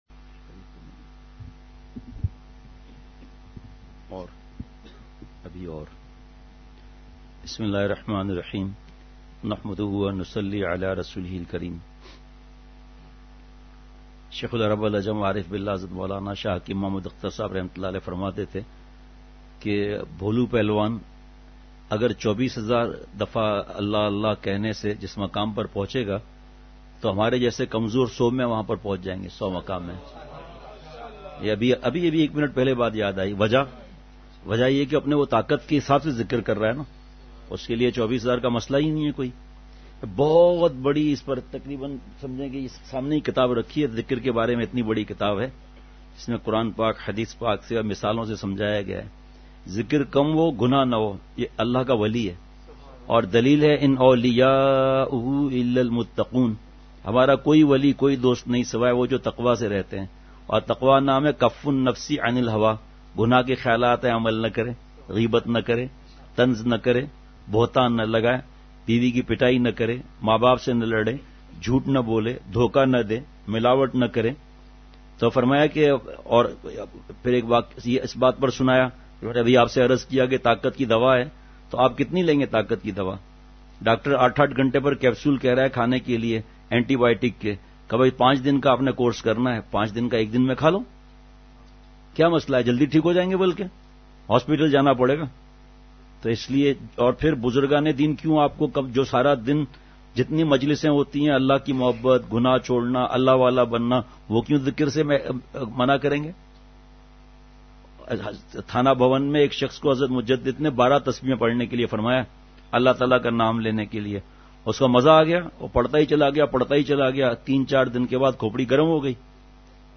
اصلاحی مجلس کی جھلکیاں